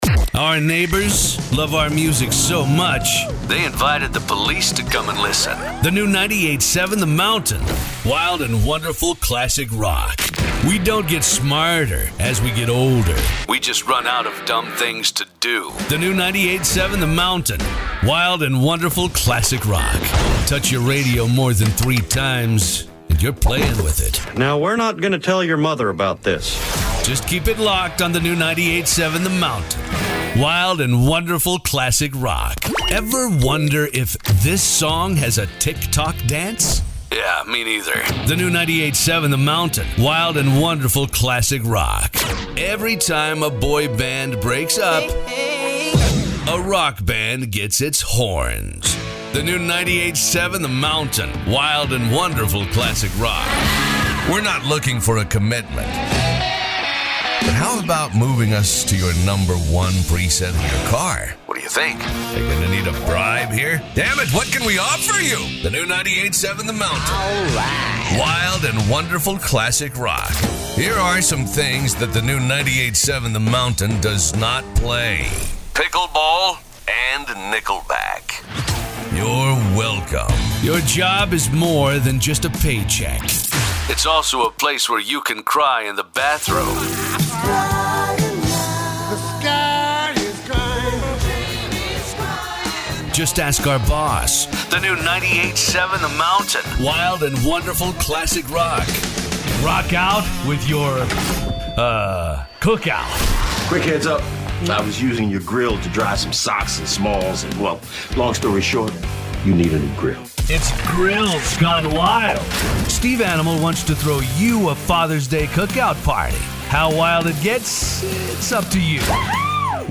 Imaging Voice Demos: